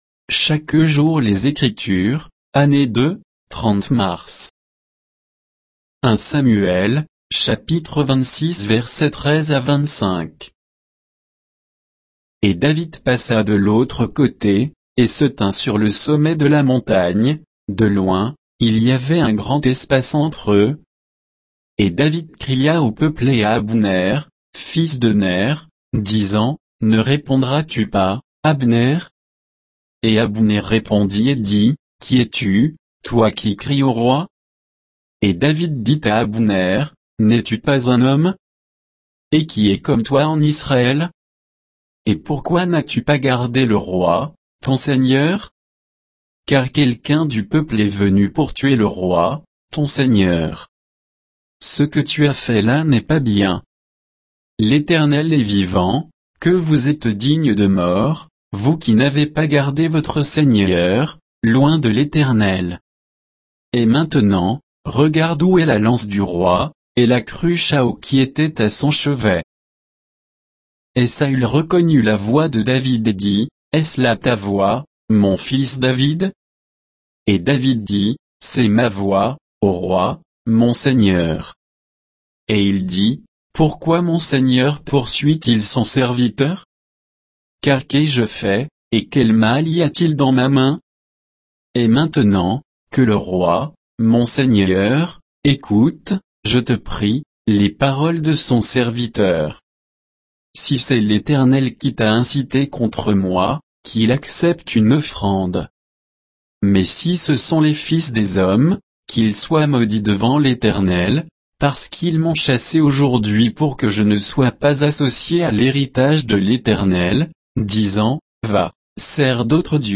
Méditation quoditienne de Chaque jour les Écritures sur 1 Samuel 26, 13 à 25